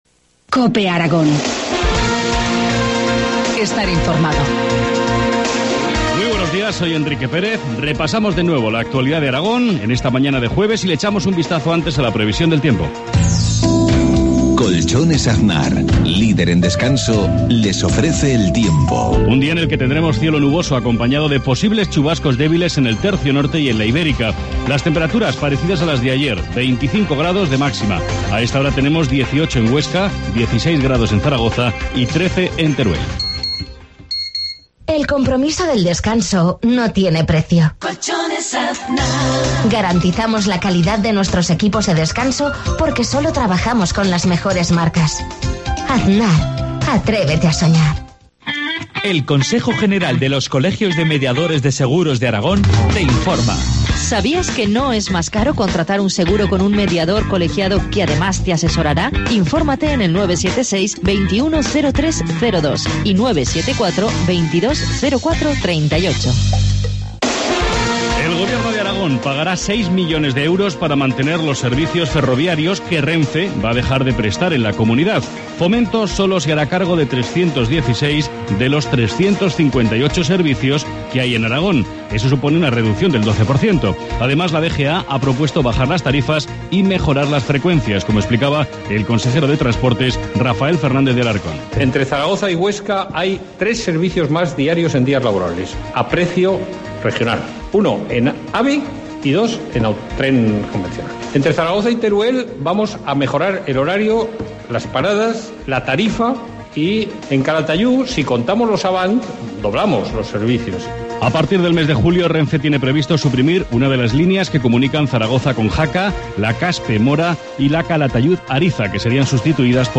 Informativo matinal, jueves 9 de mayo, 7.53 horas